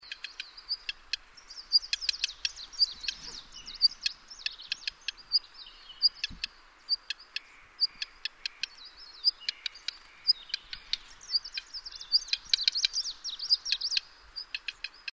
Eastern Black Redstarts Phoenicurus ochruros phoenicuroides in Altai
The following photos and sound recordings are all taken close to Aktash, Altai Republic, Russia, 24 or 25 June 2010.
Alarm calls This is a male, looked like 2nd cy alarming.